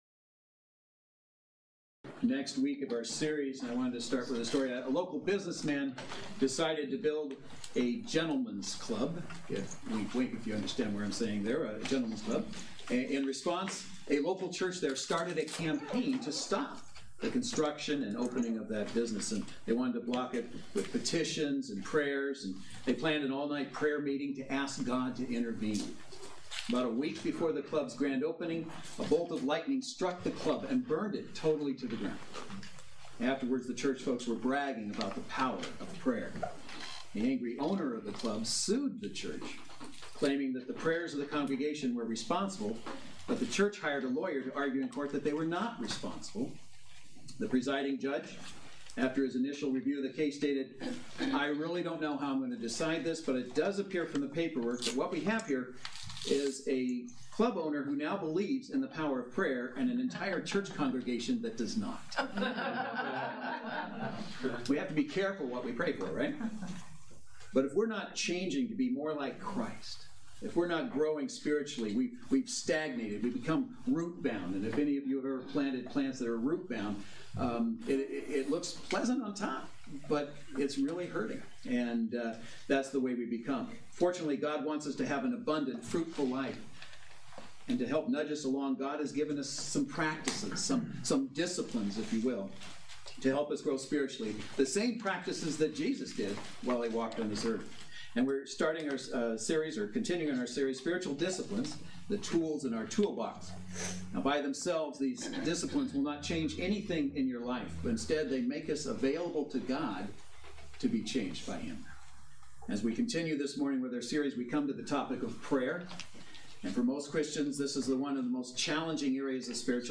Series: Spiritual Disciplines: Tools in our Toolbox Service Type: Saturday Worship Service